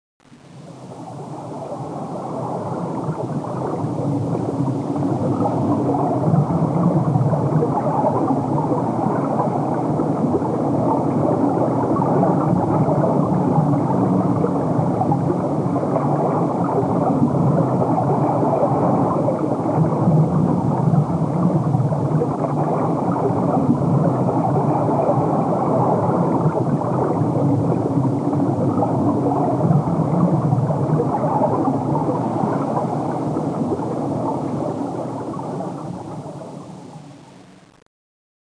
whale2.mp3